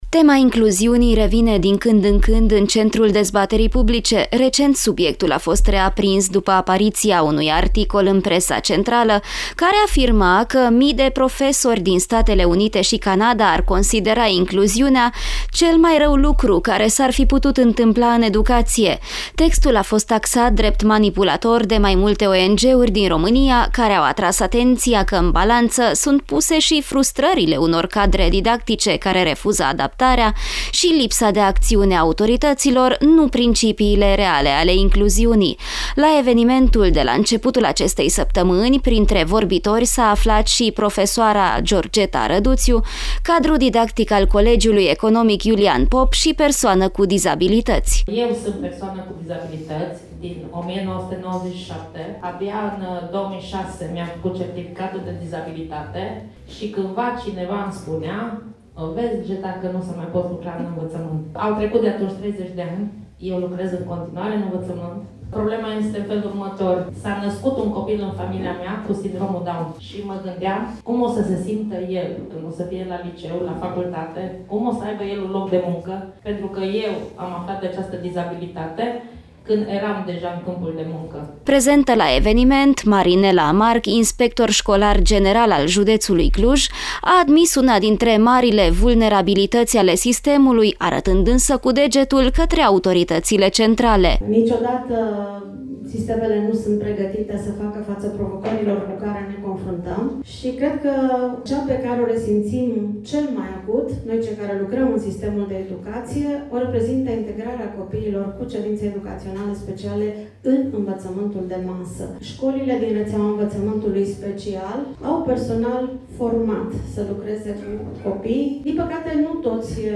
Dezbatere despre incluziune la Centrul Zbor, o temă reaprinsă de presă.
Dezbatere-incluziune-25-noiembrie.mp3